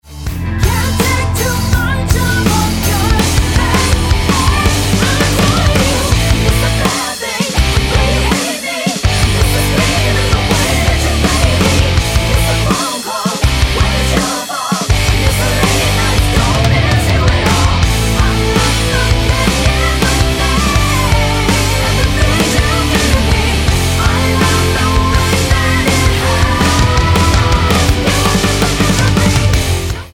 Tonart:Am mit Chor